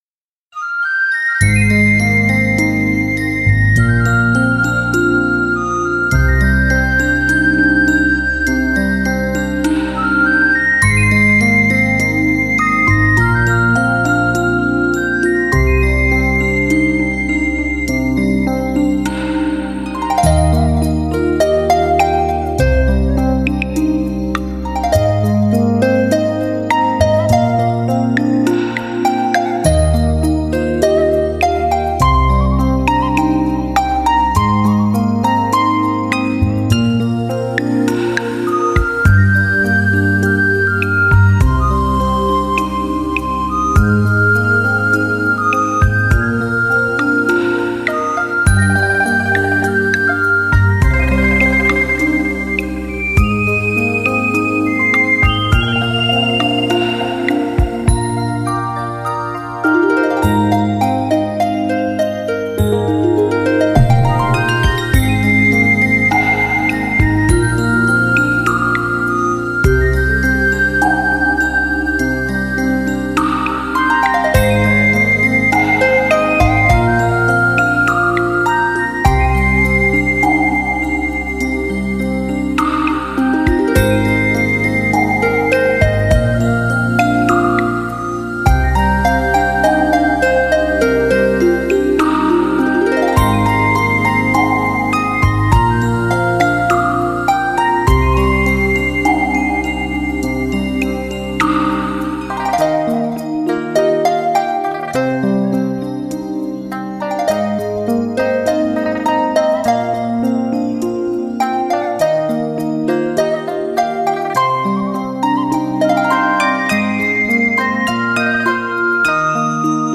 [22/11/2011]海滨纯音乐